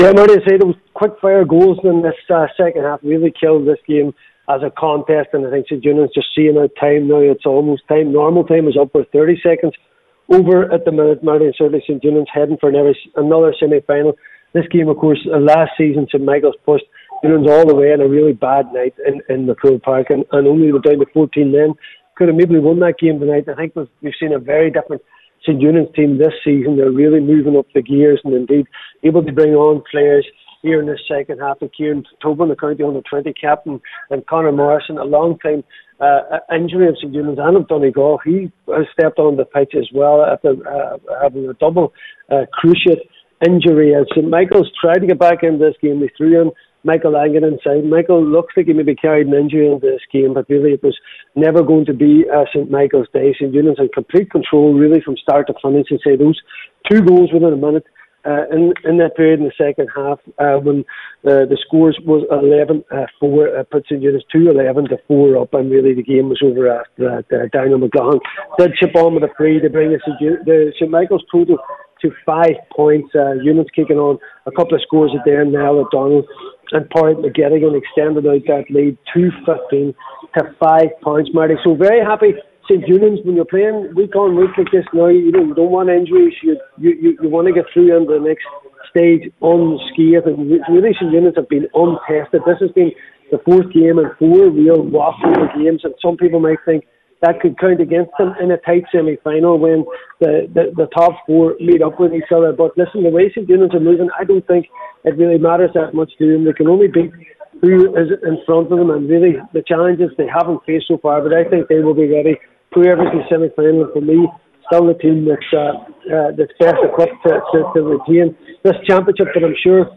full time report…